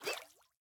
Minecraft Version Minecraft Version snapshot Latest Release | Latest Snapshot snapshot / assets / minecraft / sounds / mob / axolotl / idle_air4.ogg Compare With Compare With Latest Release | Latest Snapshot
idle_air4.ogg